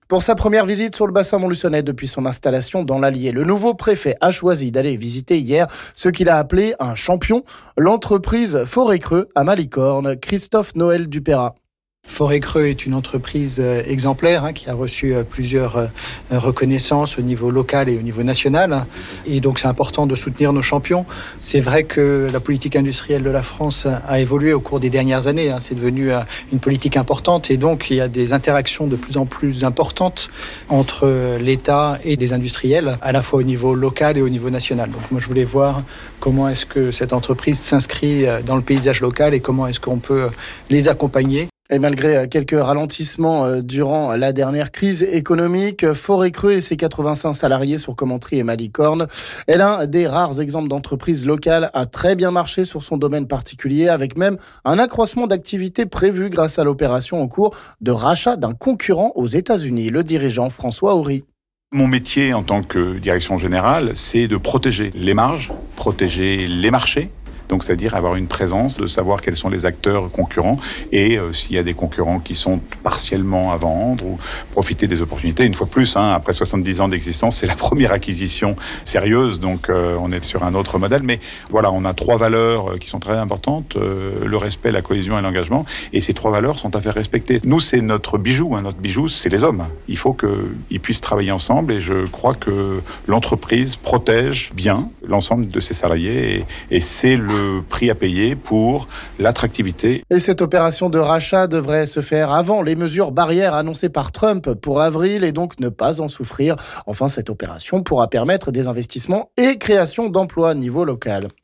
Sujet à écouter ici avec le préfet Christophe Noël du Payrat